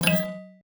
RiftMayhem/UIClick_Menu Back Denied Metal Hit.wav at master
UIClick_Menu Back Denied Metal Hit.wav